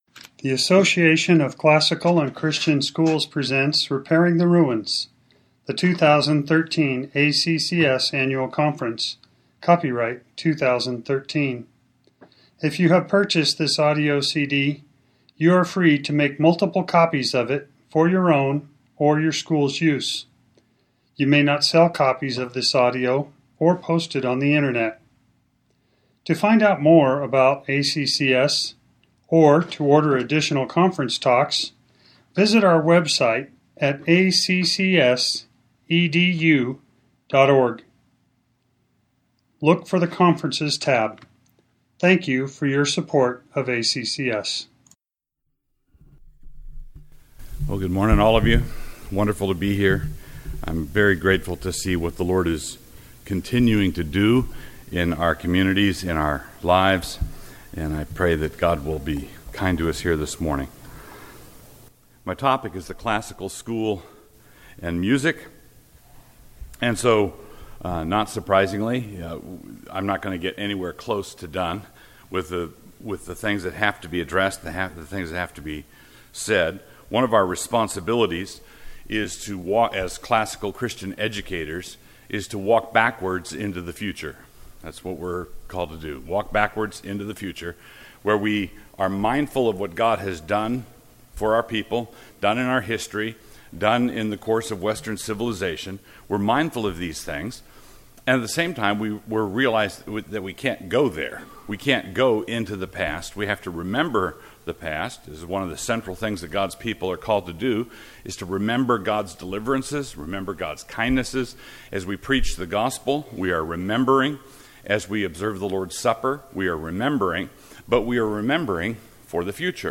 2013 Plenary Talk | 1:06:22 | All Grade Levels, Art & Music